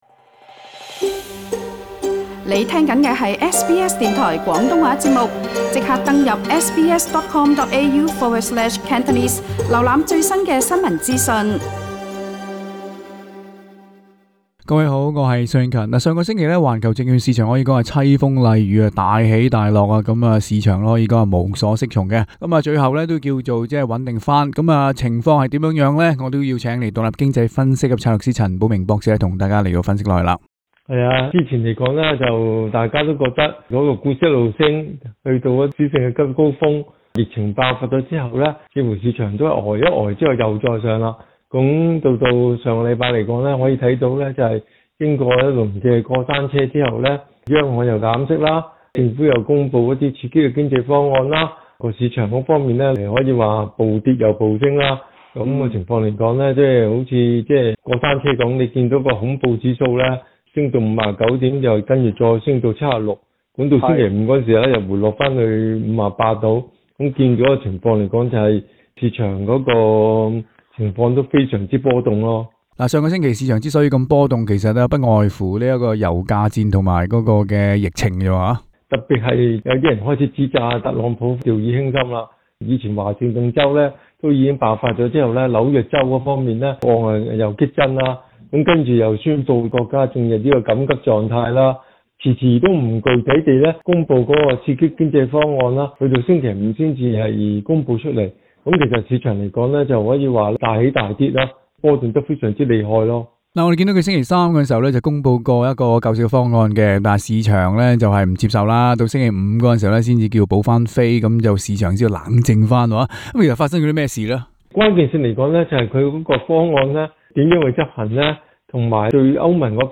SBS廣東話節目